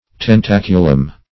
Search Result for " tentaculum" : The Collaborative International Dictionary of English v.0.48: Tentaculum \Ten*tac"u*lum\, n.; pl.